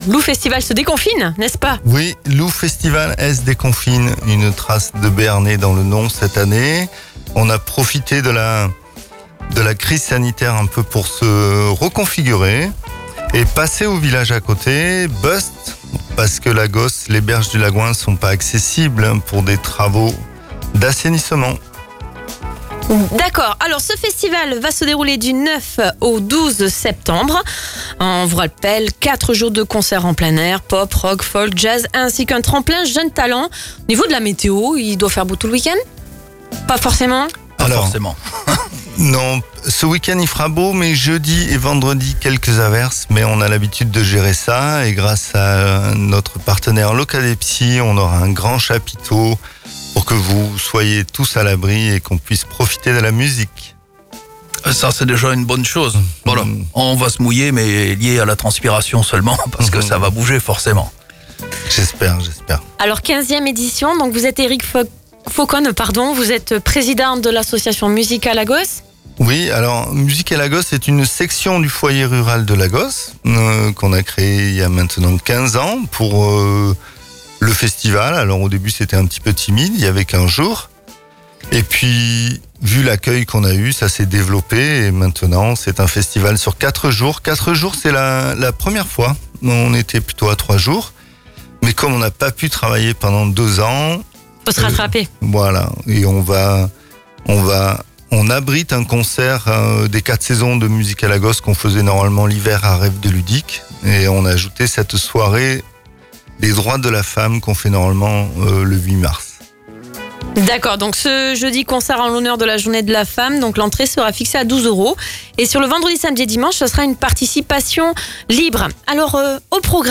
Retrouvez l'interview du Lou Festival S Dé-confine